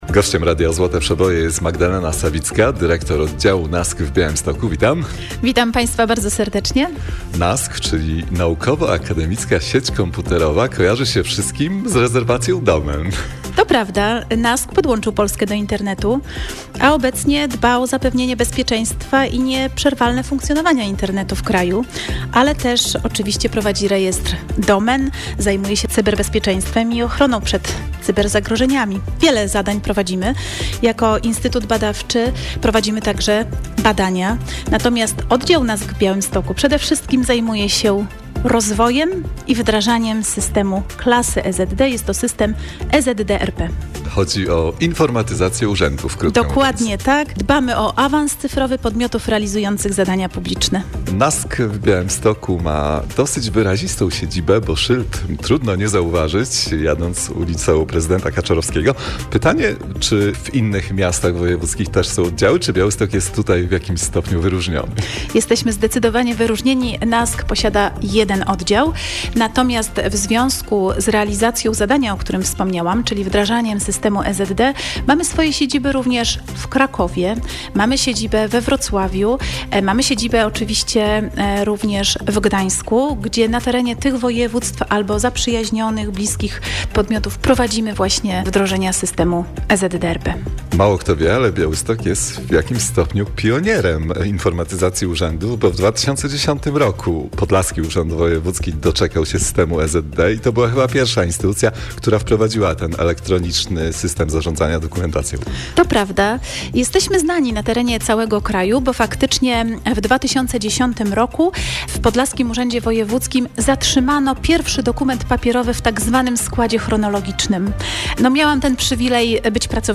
Wywiad w Radiu Złote Przeboje